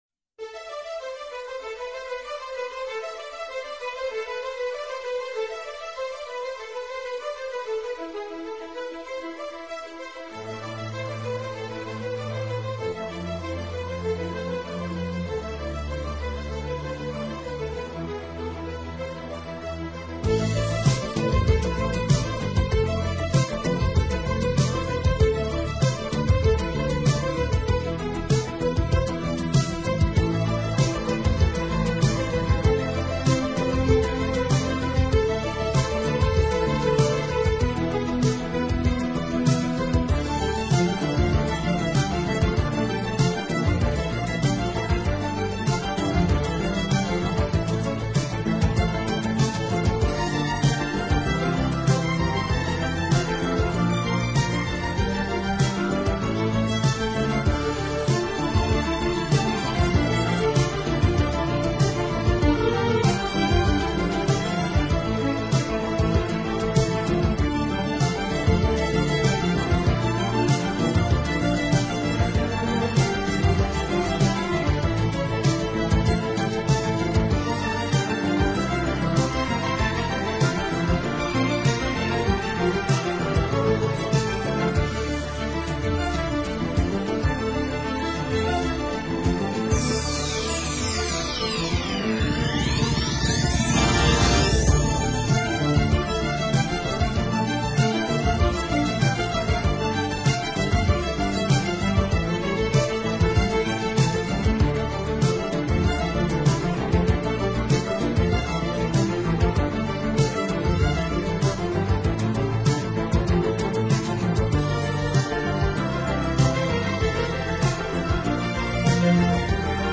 【轻音乐专辑】
这就是古典元素（Oboe, Flauto, Corno, Archi, Pianoforte）和现代元
素（Batteria, Chitarra Basso, Synth）如何在作品当中相互融合的过程。